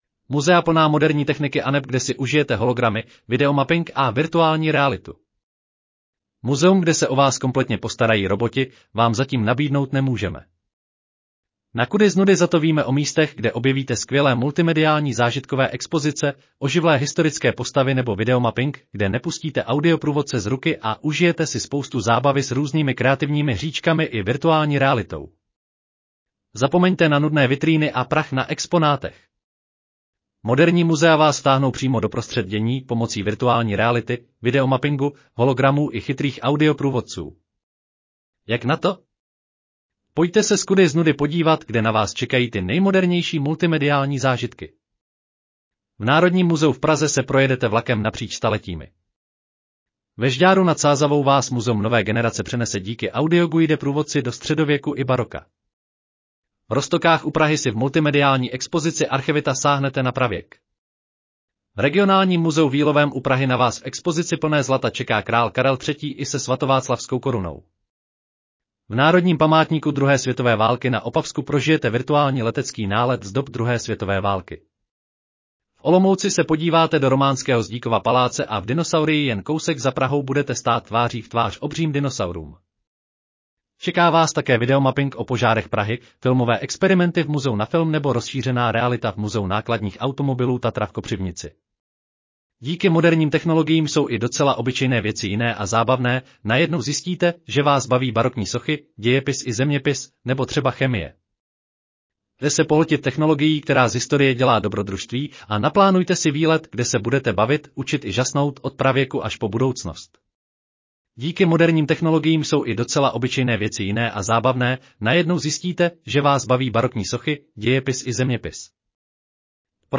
Audio verze článku Muzea plná moderní techniky aneb kde si užijete hologramy, videomapping a virtuální realitu